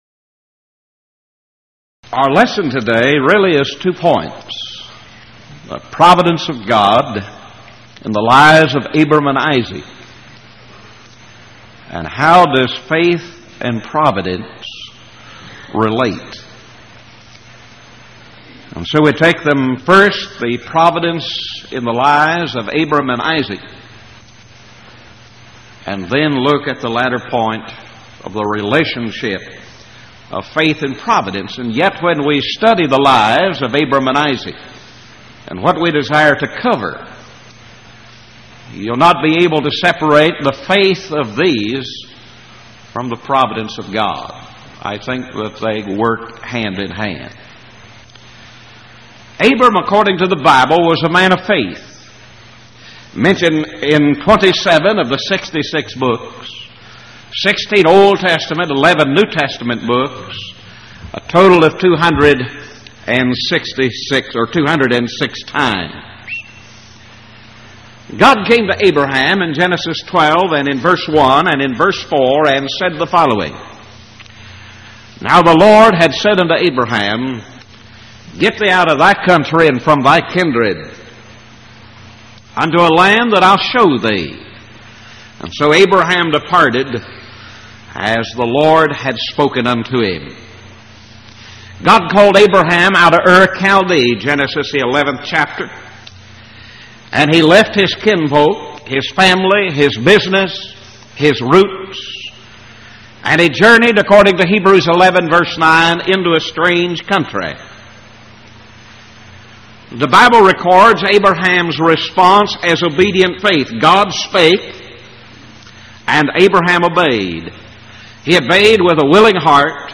Series: Power Lectures Event: 1989 Power Lectures